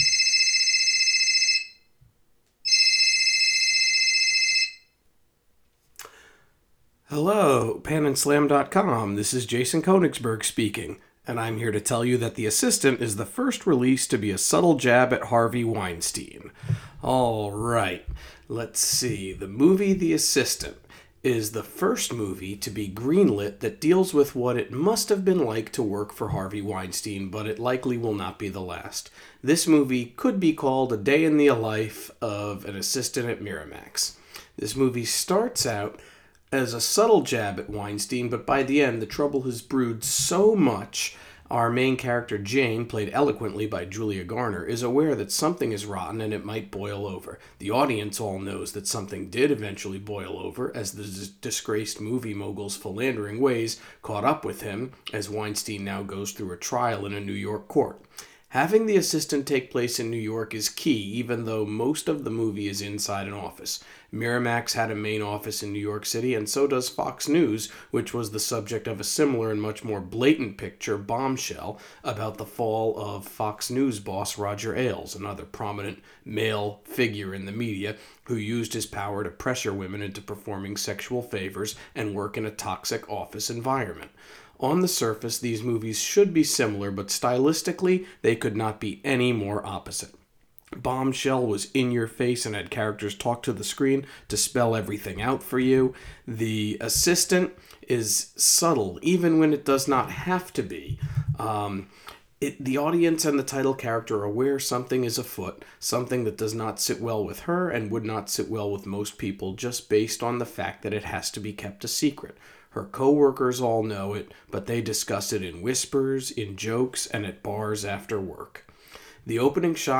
Movie Review: The Assistant